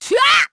Seria-Vox_Attack3_kr.wav